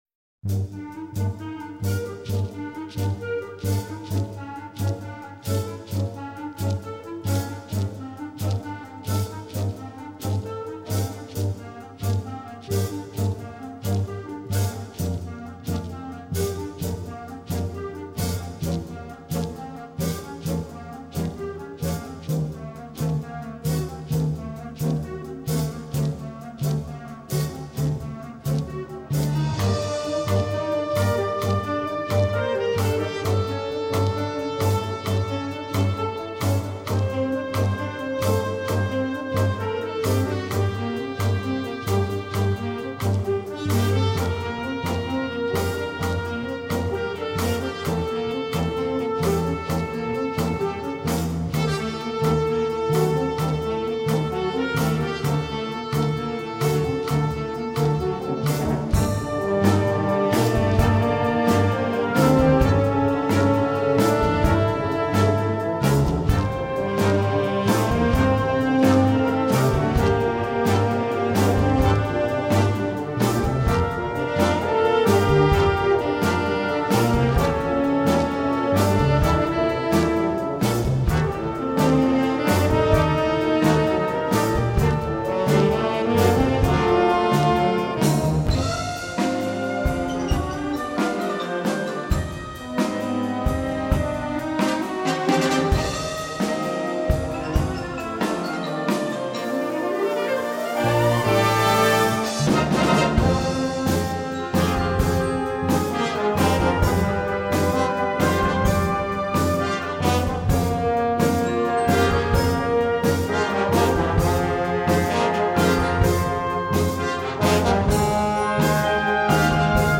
Gattung: Tango
Besetzung: Blasorchester